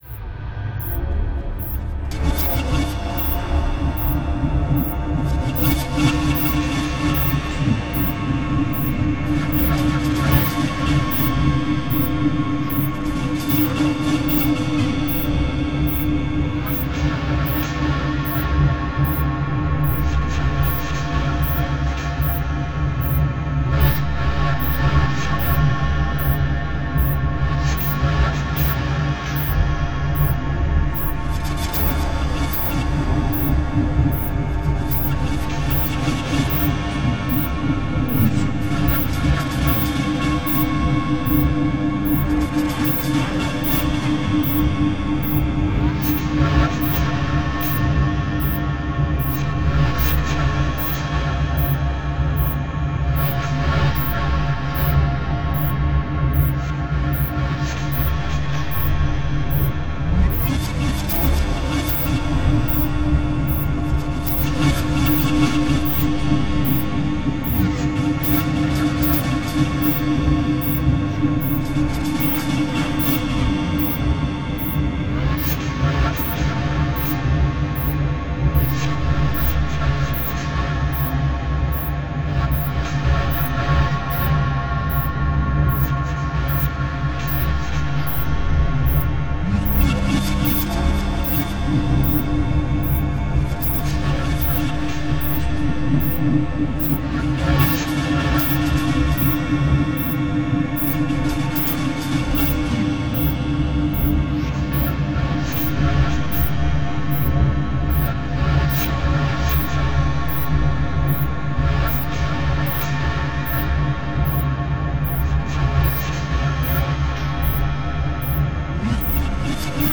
複雑な打楽器のループ・シーケンス（"Pulse"）と、
豊富な倍音を含んだ通奏持続音（"Drone"）の組み合わせが、
超音波帯域を含んで、可聴域を超える高周波から低周波までの全帯域で、
たいへん複雑・緻密なエンベロープ、パンニング、倍音のコントロールが施されている。